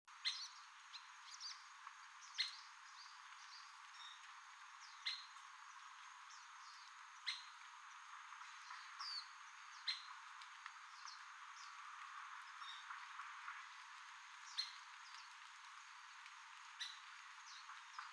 74-2小啄木2蘭潭2013.WAV
物種名稱 小啄木 Dendrocopos canicapillus kaleensis
錄音地點 嘉義市 西區 蘭潭
錄音環境 雜木林
行為描述 鳴叫 錄音器材 錄音: 廠牌 Denon Portable IC Recorder 型號 DN-F20R 收音: 廠牌 Sennheiser 型號 ME 67